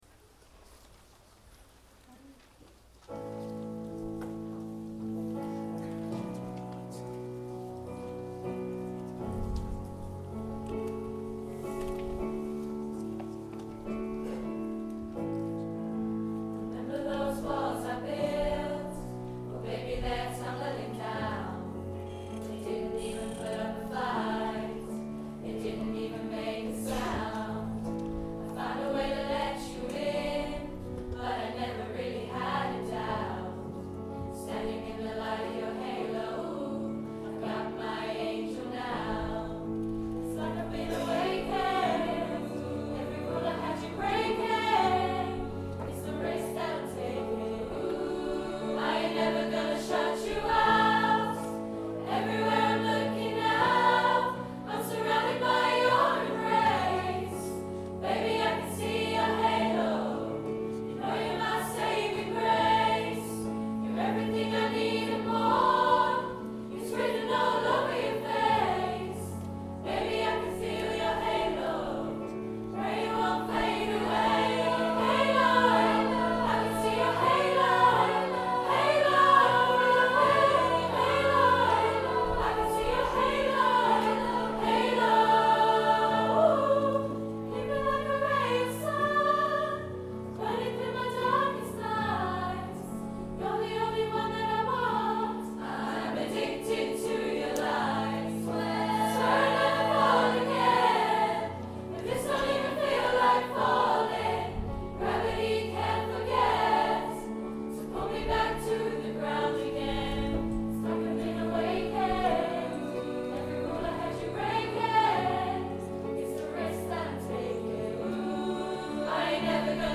Coro and Orchestra Nov 2016